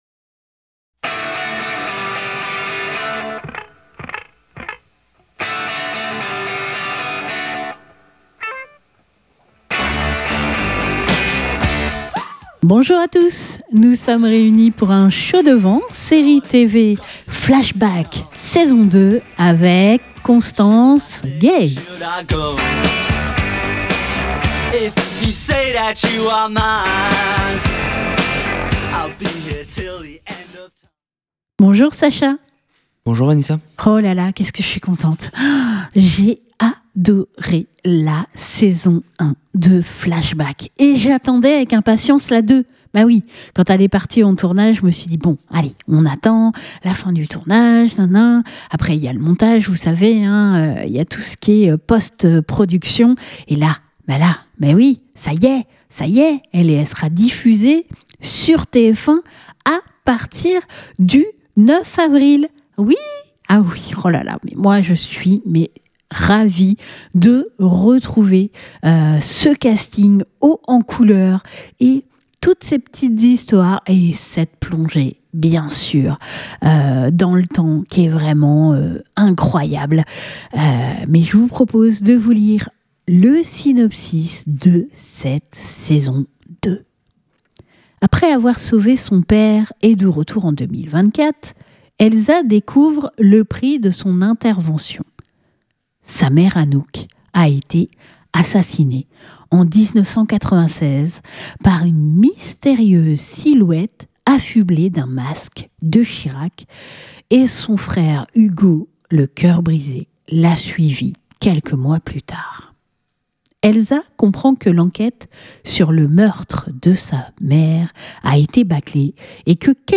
Nous vous mettons l'eau à la bouche de cette deuxième saison en compagnie de Constance Gay qui nous accorde une entrevue téléphonique.